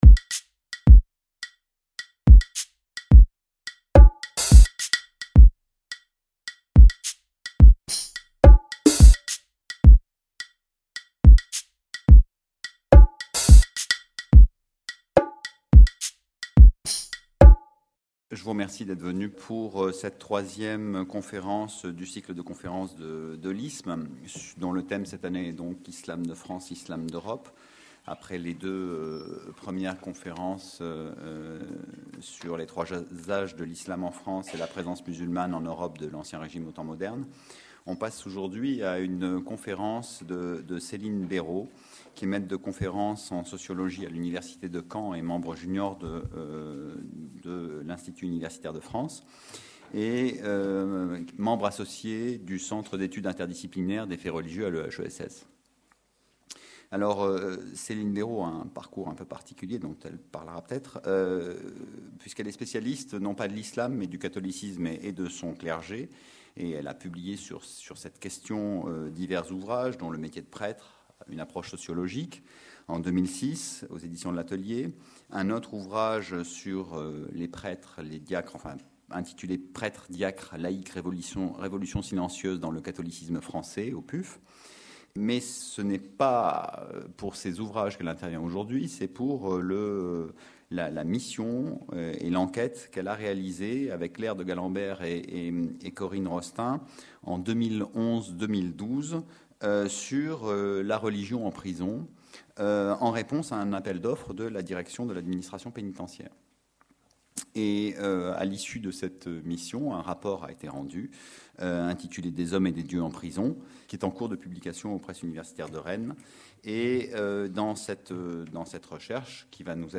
Le cycle de conférences publiques que propose l’IISMM entend restituer la diversité de ces islams de France et éclairer les enjeux et les non-dits des débats que suscite cette présence musulmane dans l’un des pays pro- moteurs de la laïcité. Les différentes interventions, souvent à plusieurs voix, proposeront ainsi, par touches successives, une analyse distanciée, éclairée par des comparaisons avec d’autres pays